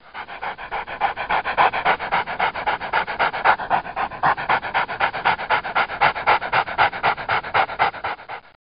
Dog_Breath.mp3